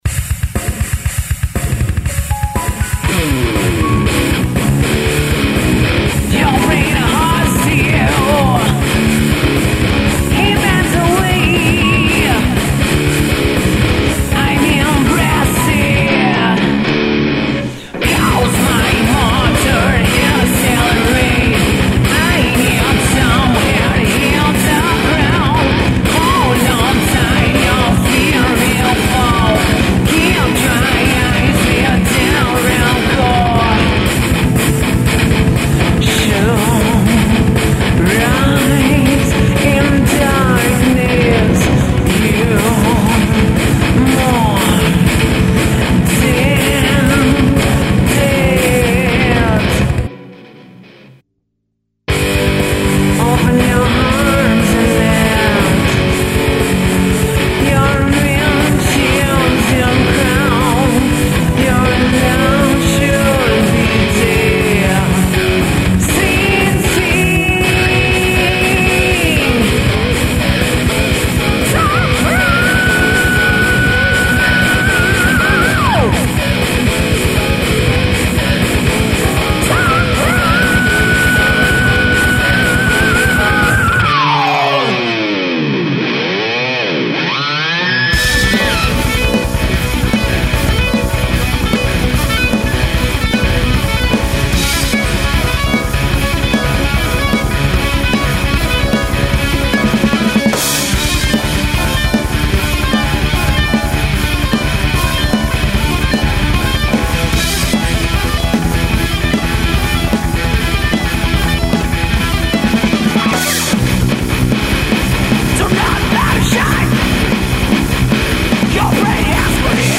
Vocals
Bass
Execution Drums..